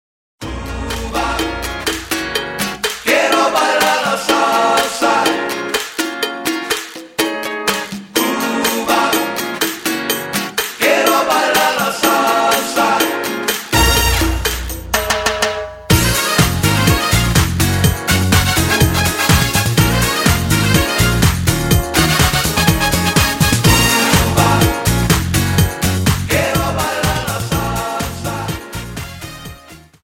Dance: Cha Cha Song